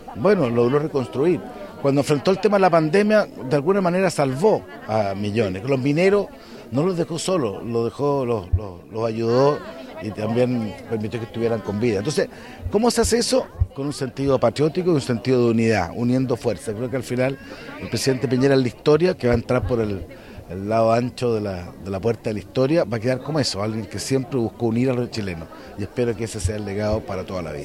La ceremonia también contó con la presencia del senador de la UDI, Juan Antonio Coloma, quien destacó algunos hitos de las administraciones del exmandatario, señalando que su principal legado es que siempre buscó la unidad en el país.